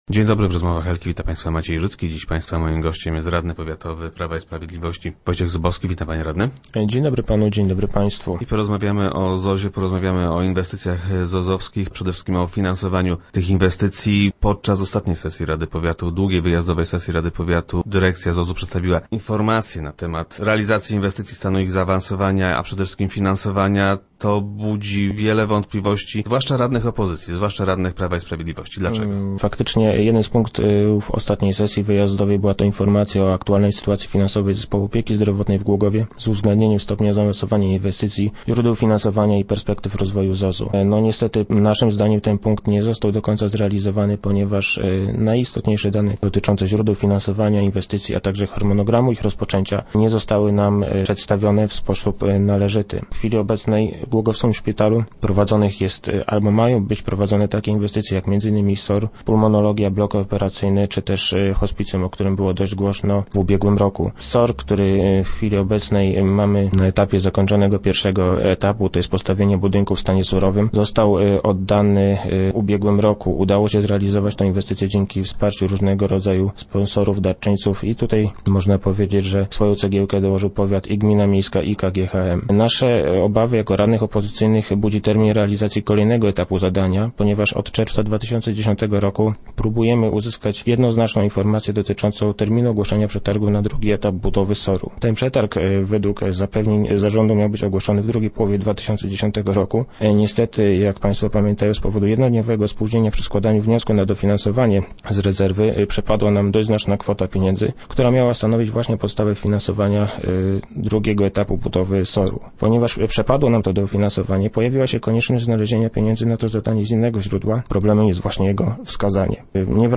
Radni opozycji mają jednak wątpliwości dotyczące finansowa szpitalnych inwestycji. Gościem Rozmów Elki był Wojciech Zubowski, radny powiatowy PiS.